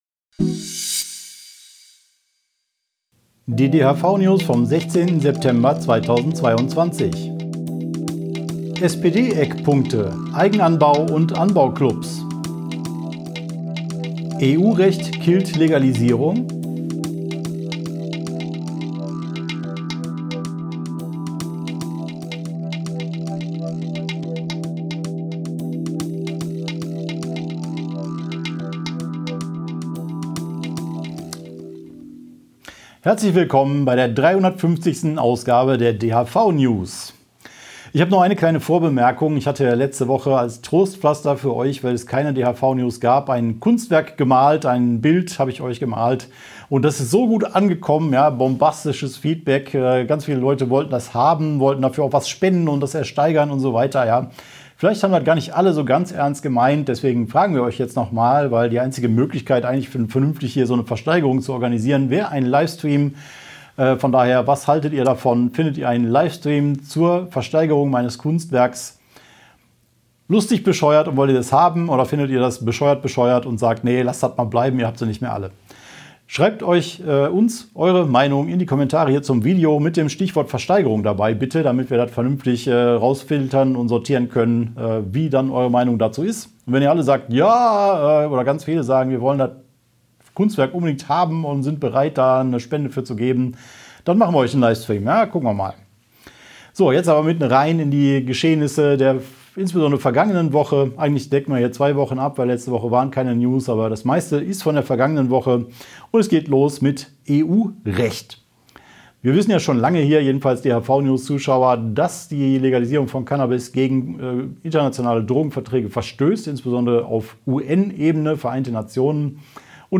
| DHV-Video-News #350 Die Hanfverband-Videonews vom 16.09.2022 Die Tonspur der Sendung steht als Audio-Podcast am Ende dieser Nachricht zum downloaden oder direkt hören zur Verfügung.